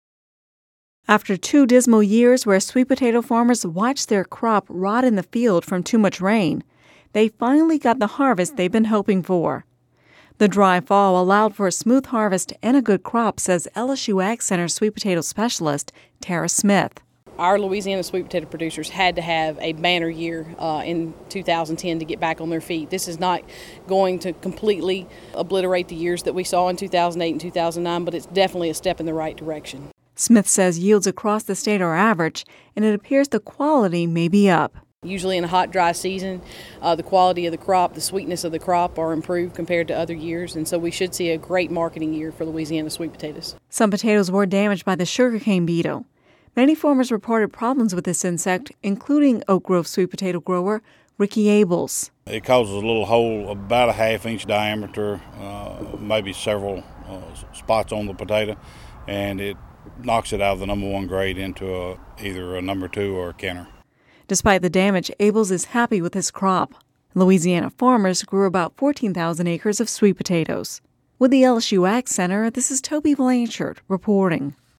(Radio News 11/22/10) After two dismal years where Louisiana sweet potato farmers watched their crops rot in the field from too much rain, they finally got the harvest they’ve been hoping for this year.